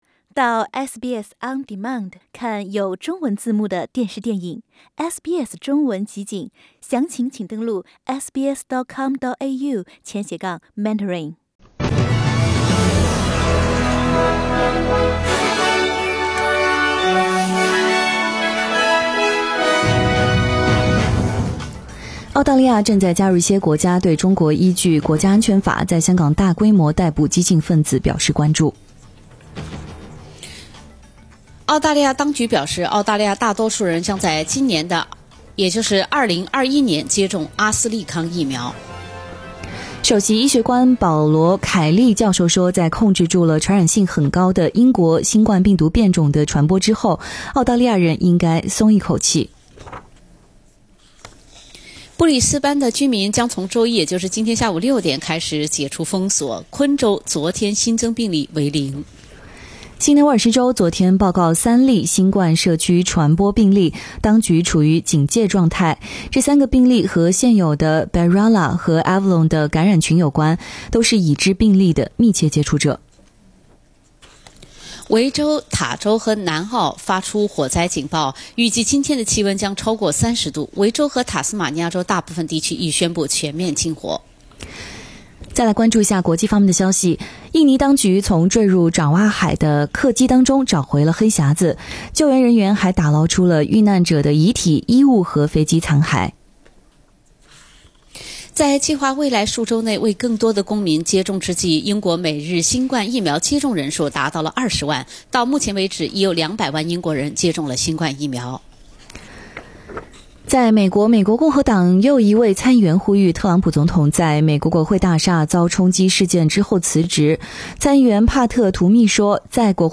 SBS早新聞（1月11日）